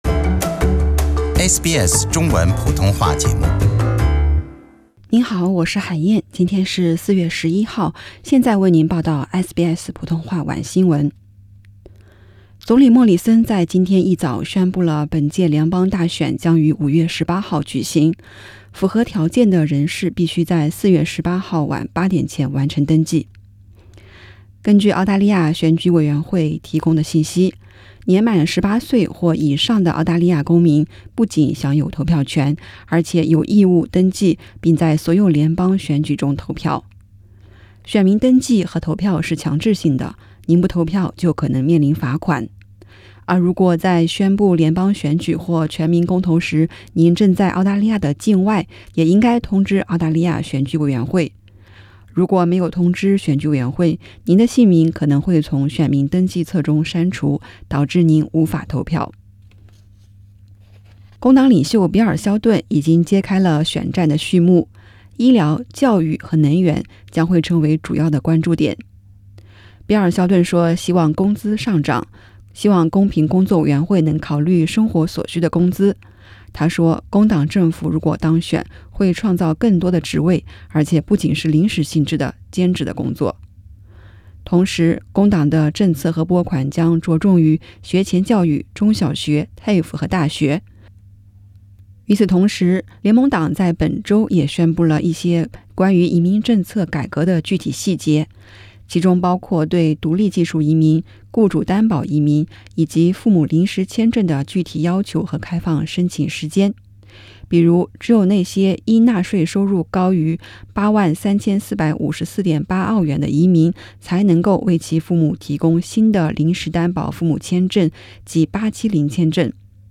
SBS 早新闻 （4月12日）